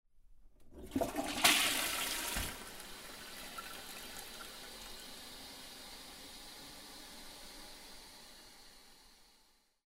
Toilet Sound Effects
Toilet Flush 1 00:11 wav aif
Toilet-Flush-Short_01.mp3